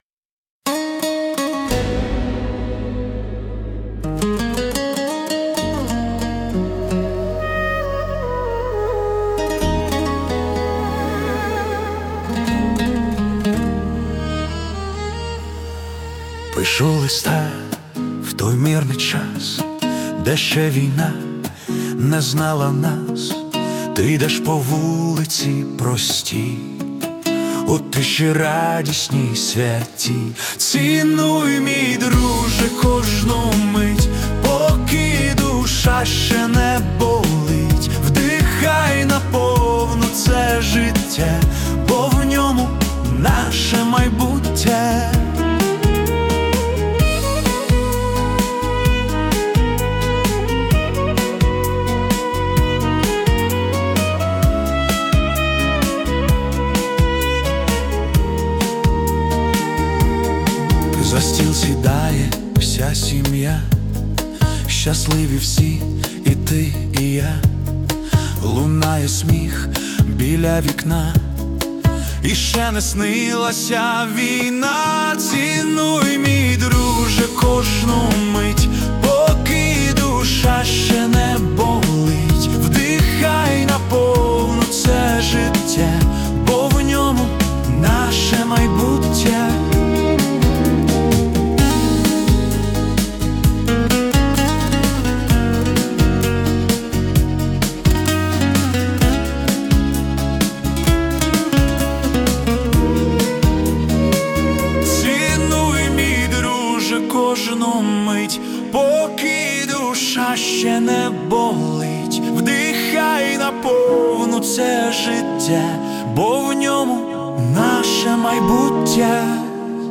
Melodic Pop / Philosophical